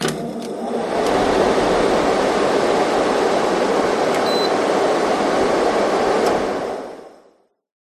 На этой странице собраны натуральные звуки обогревателей и каминов: от монотонного гула масляного радиатора до живого потрескивания дров в очаге.
Встроенный обогреватель и кондиционер включаются потом выключаются 1